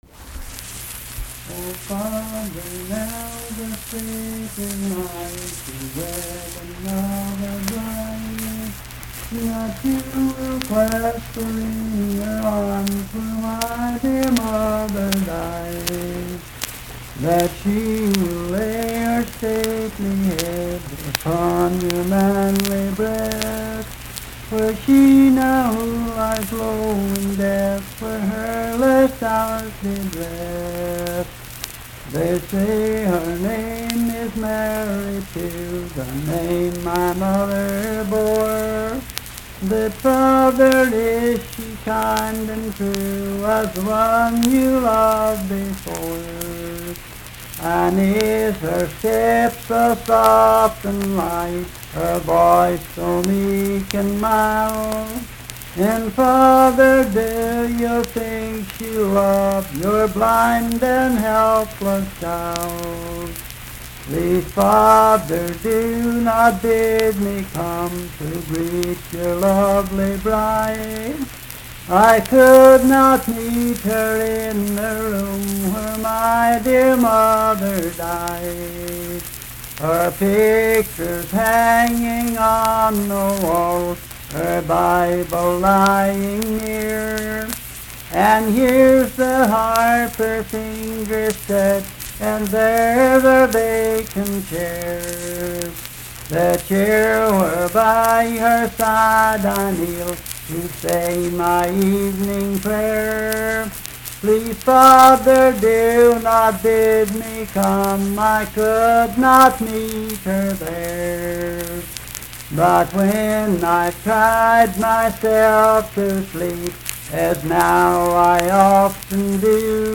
Unaccompanied vocal performance
Voice (sung)
Randolph County (W. Va.)